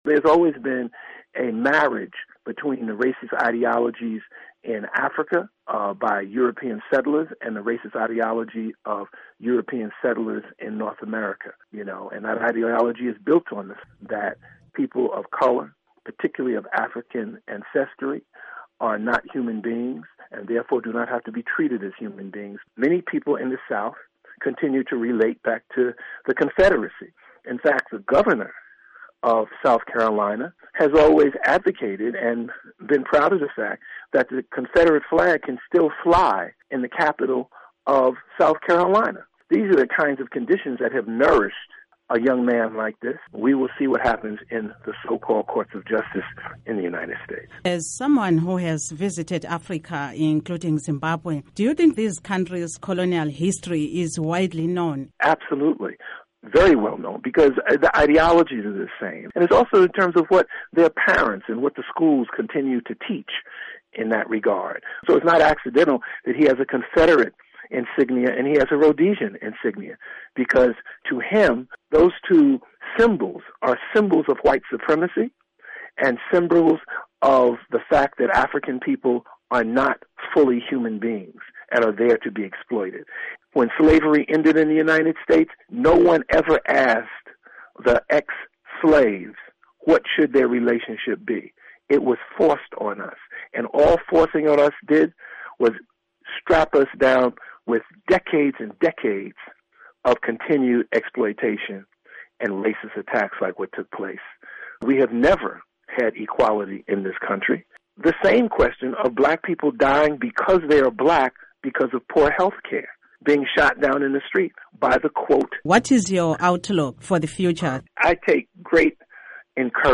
Interview with Civil Rights Leader